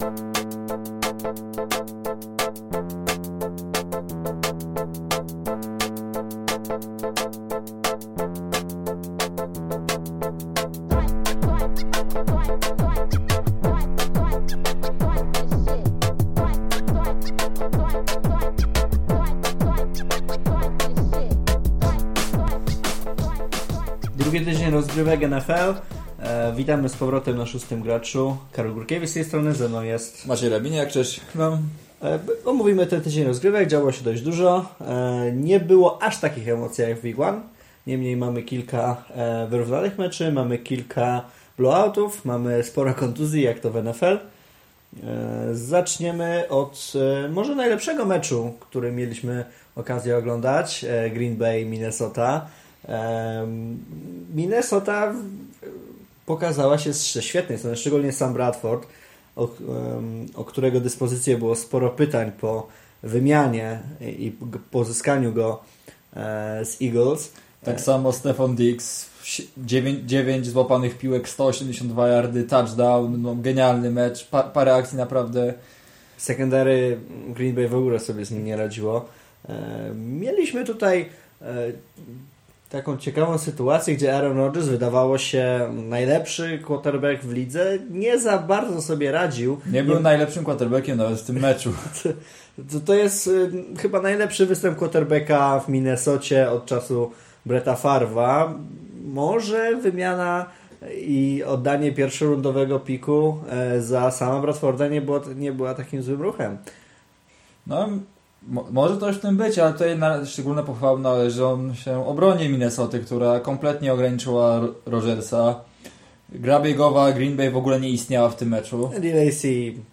W końcu w dobrej jakości (!).